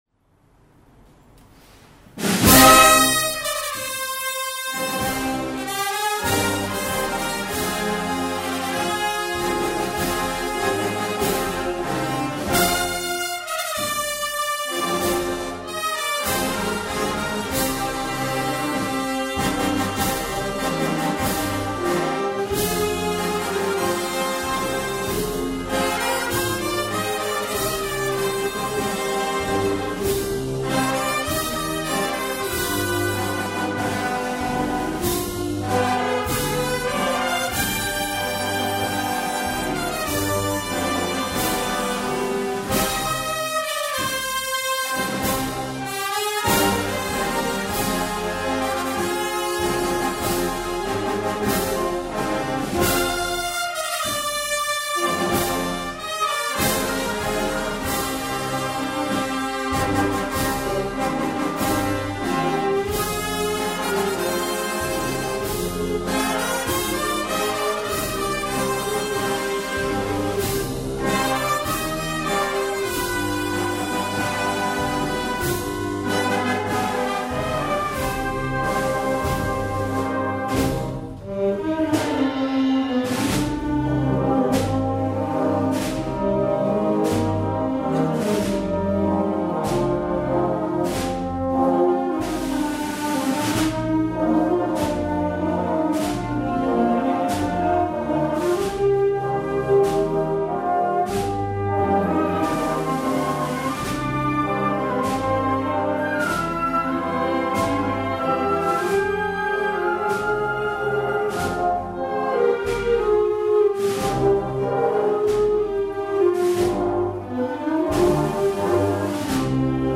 Marcha Procesional para Banda de Música,